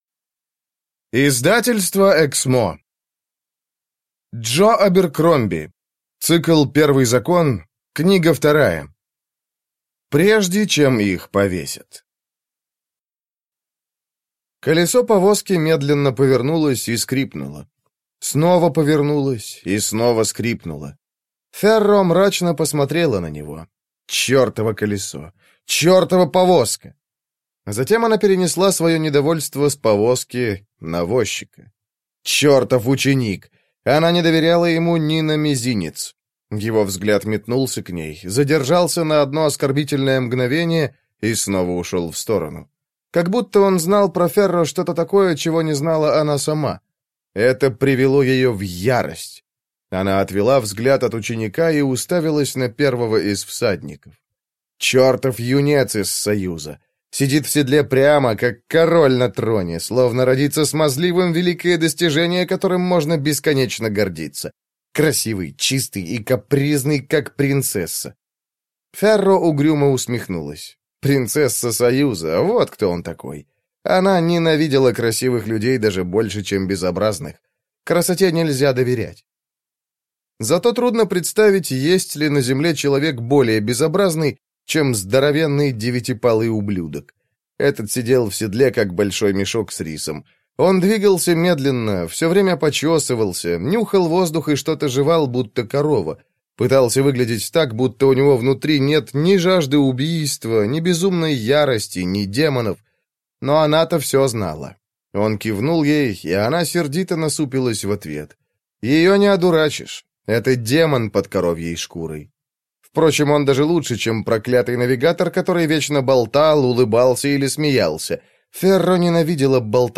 Аудиокнига Прежде, чем их повесят | Библиотека аудиокниг